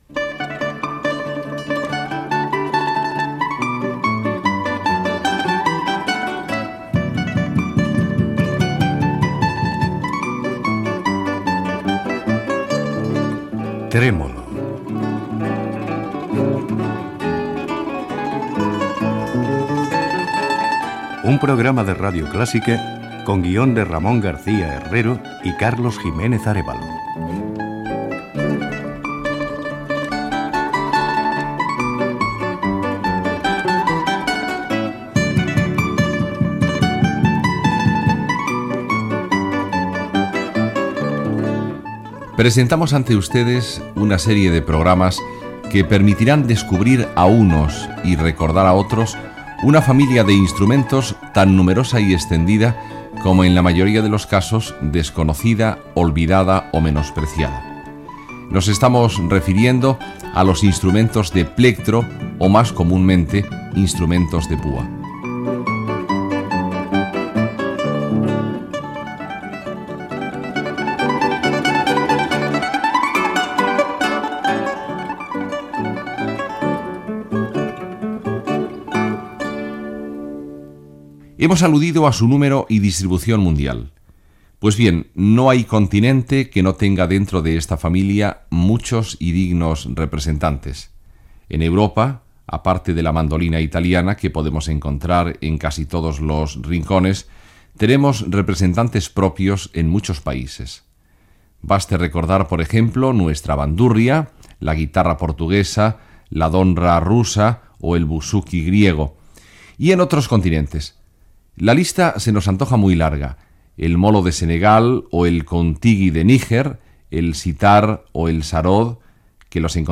Careta del programa, presentació de l'espai dedicat als instruments de pua amb una enumeració i exemples d'instruments japonesos i indis.
Musical